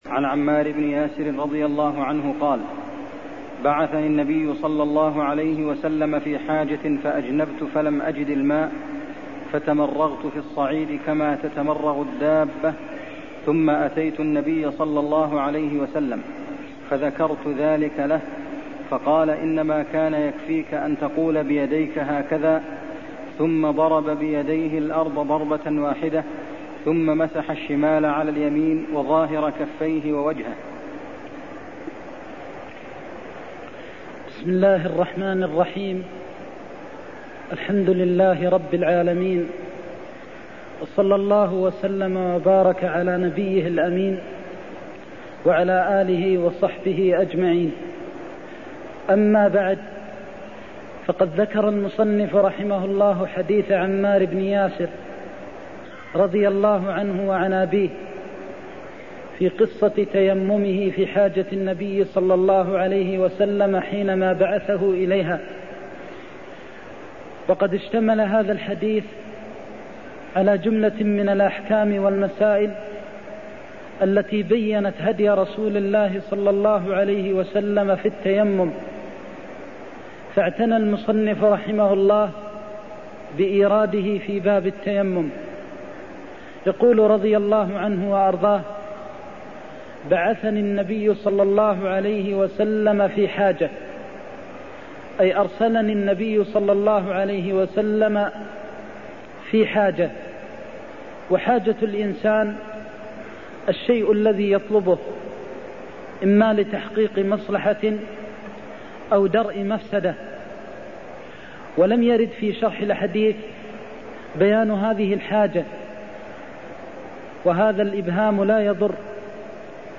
المكان: المسجد النبوي الشيخ: فضيلة الشيخ د. محمد بن محمد المختار فضيلة الشيخ د. محمد بن محمد المختار إنما كان يكفيك أن تقول بيديك هكذا (37)